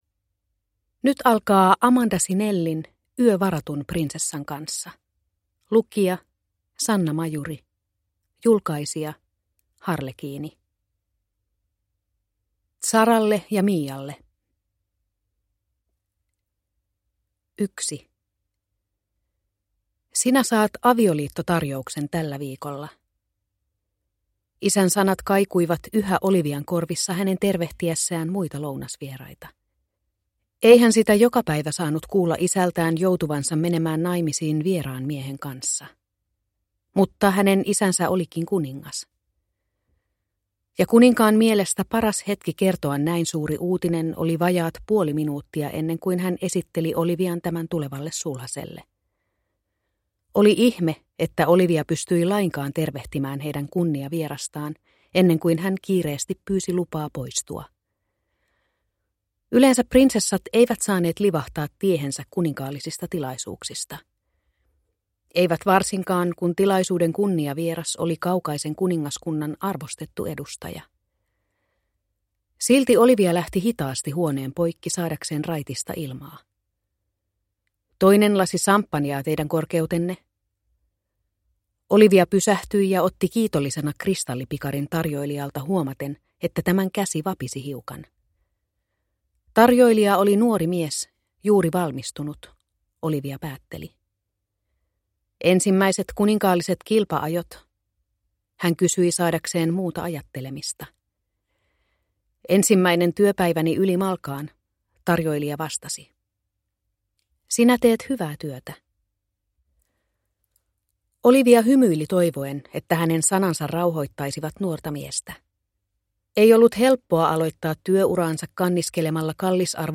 Yö varatun prinsessan kanssa – Ljudbok